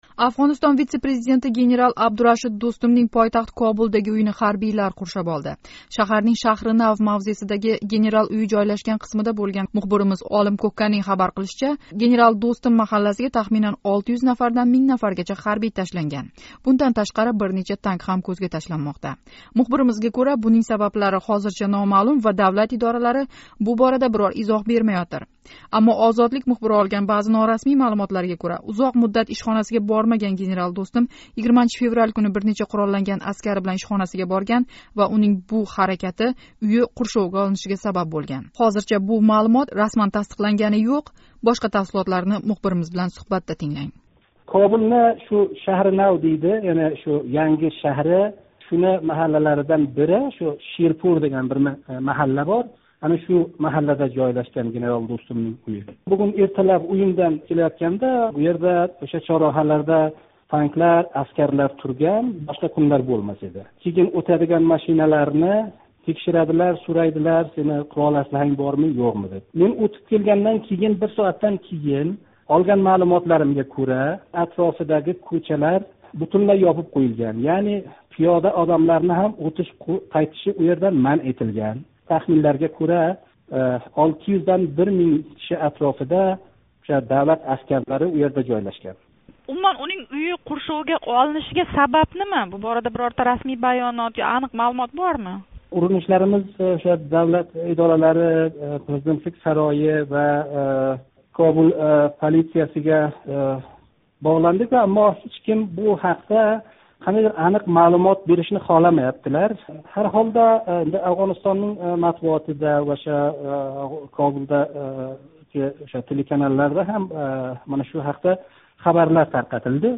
Мухбиримиз билан қуйидаги суҳбатда воқеа тафсилотларига доир қўшимча маълумотлар билан танишасиз: